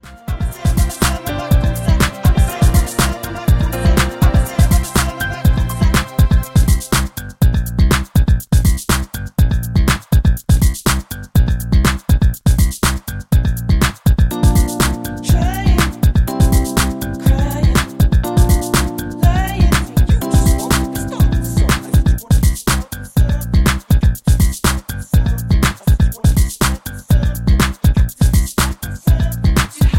Backing track Karaoke
Pop, Disco, 2000s